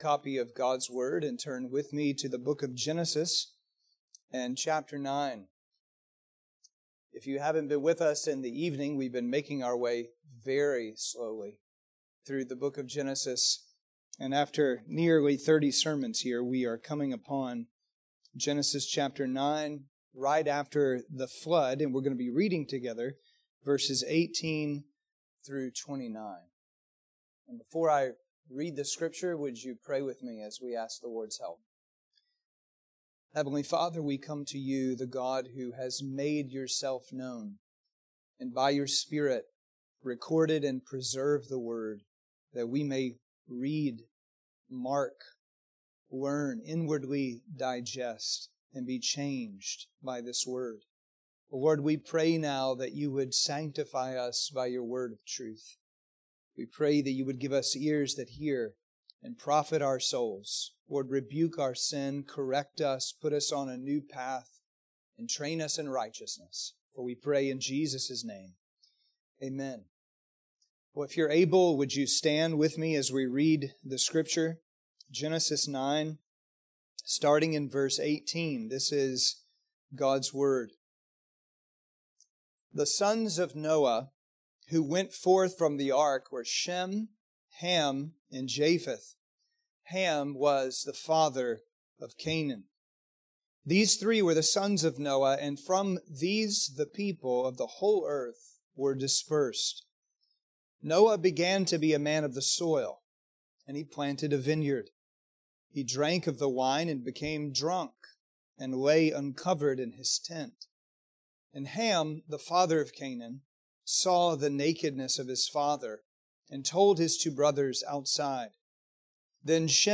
Sermons and Adult Sunday School from Grace Presbyterian Church, Douglasville, Georgia